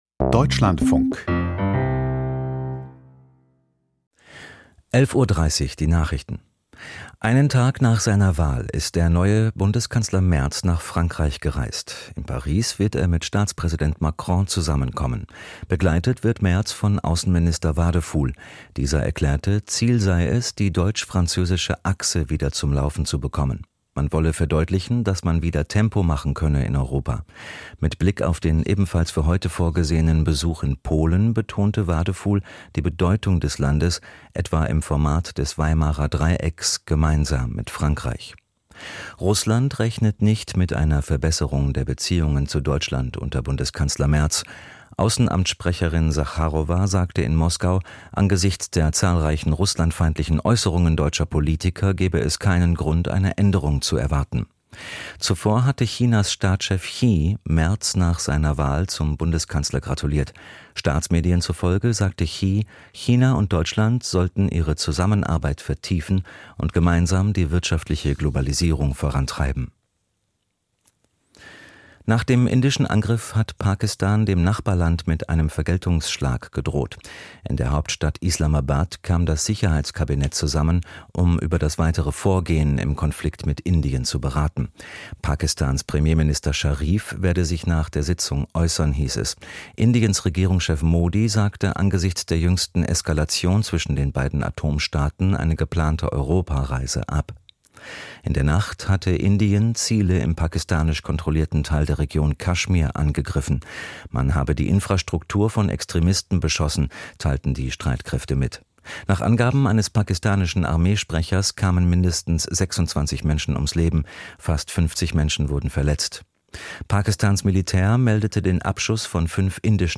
Die Deutschlandfunk-Nachrichten vom 07.05.2025, 11:30 Uhr